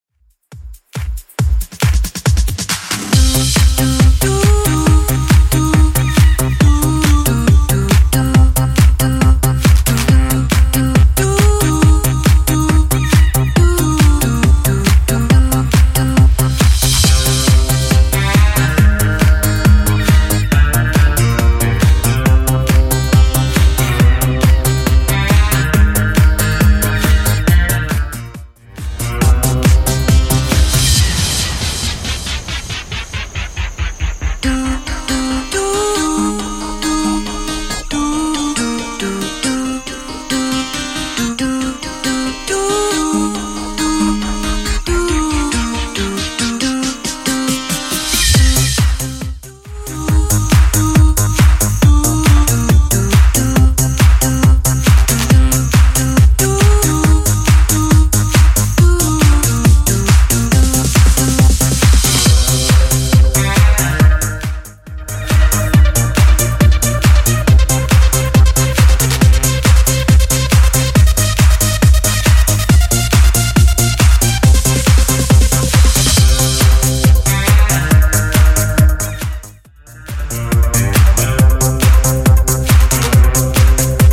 Genre: 80's